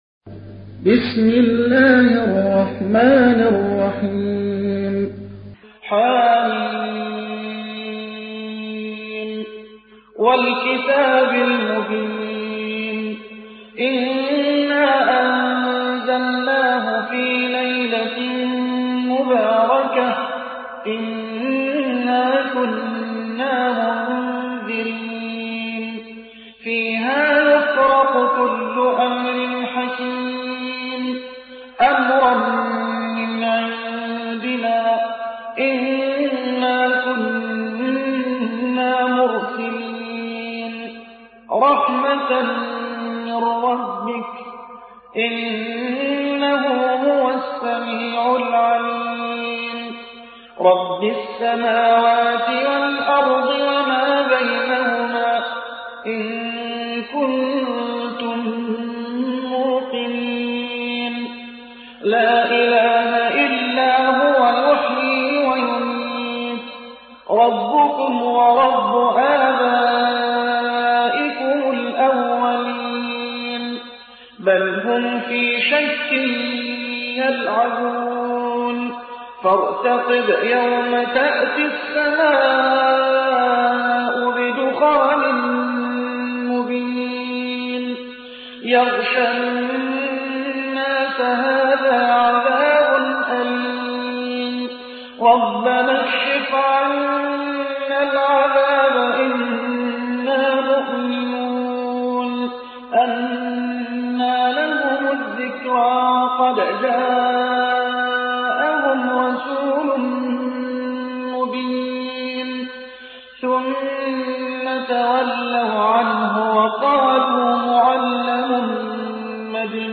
تحميل : 44. سورة الدخان / القارئ محمد حسان / القرآن الكريم / موقع يا حسين